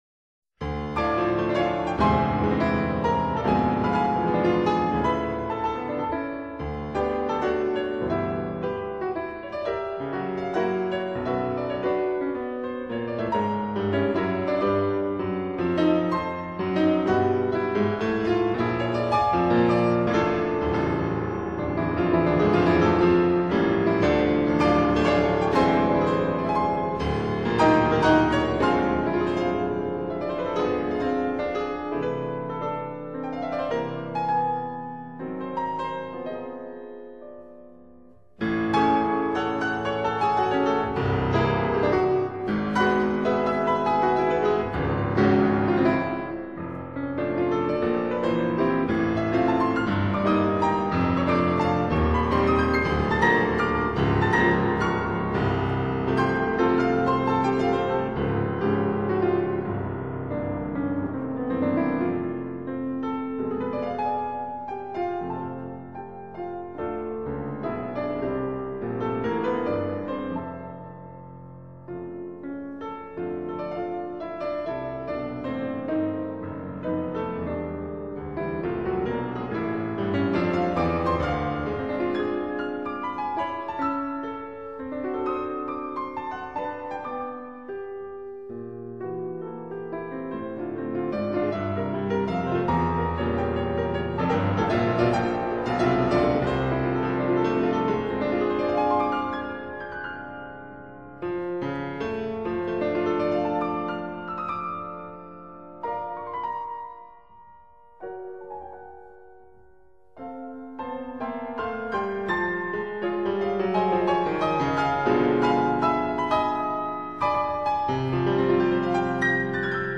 其作品為後浪漫主義風格，氣魄宏大，旋律優美，配器生動，唯風格略嫌保守。
piano